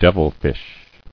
[dev·il·fish]